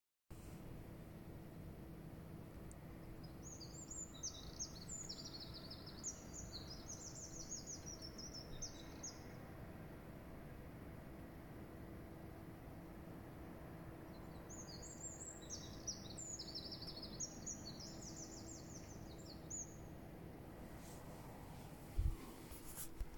Hva slags fuglesang
Hei, jeg lurer hva slags art jeg hørte synge kl 0130 i natt.